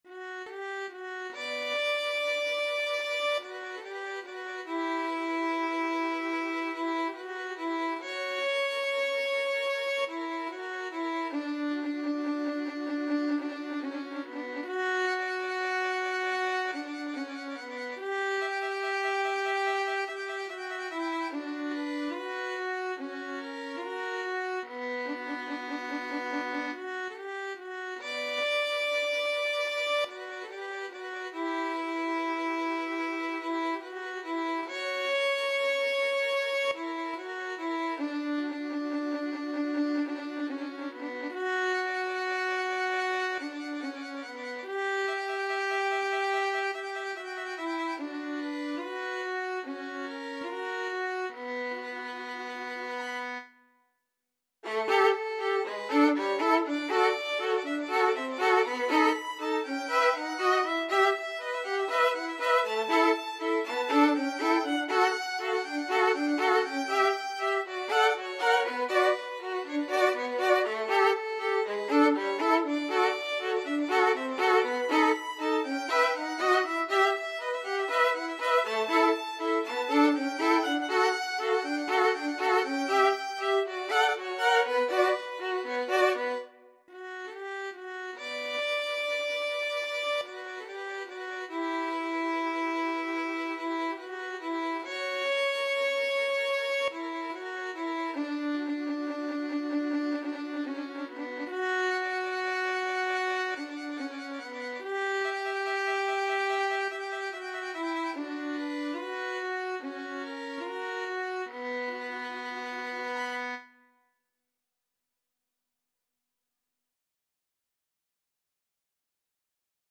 Violin 1Violin 2
Poco sostenuto =c.72
2/4 (View more 2/4 Music)
Violin Duet  (View more Intermediate Violin Duet Music)
Classical (View more Classical Violin Duet Music)